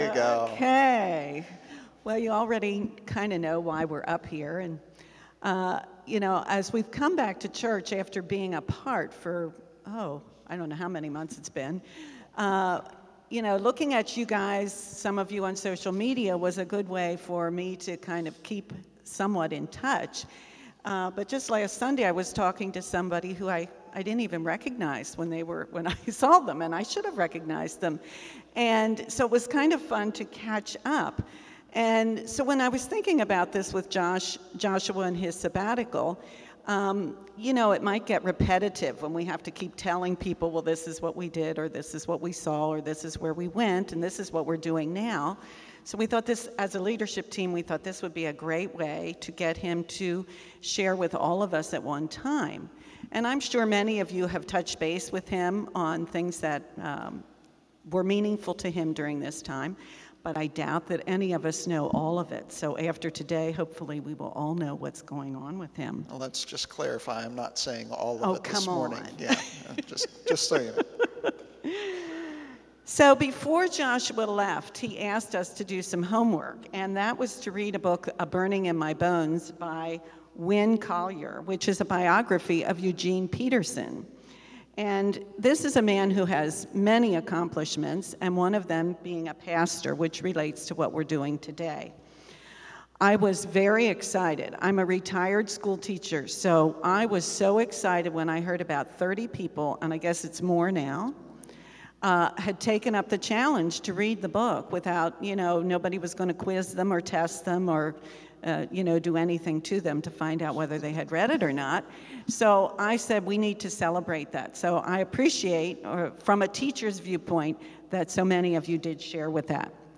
Message: “Easter Sunday” from Multiple People